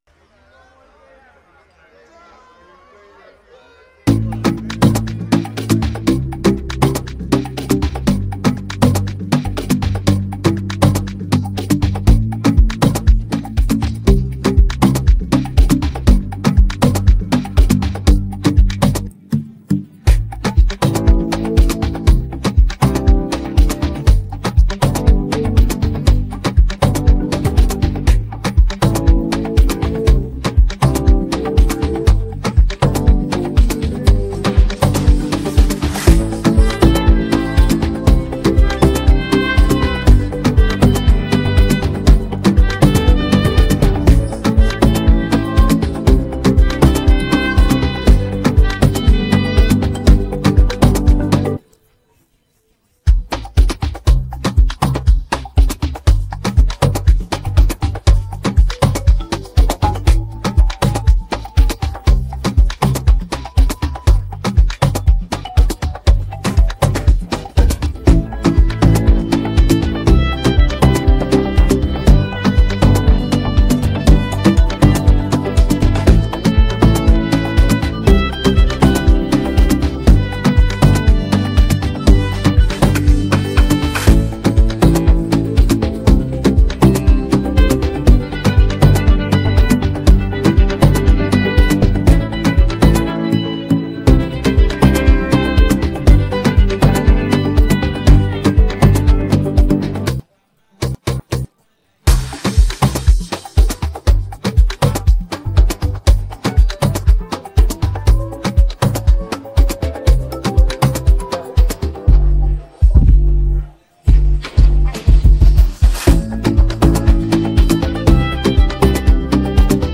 With its catchy melodies and rhythmic beats